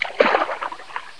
waterpad.mp3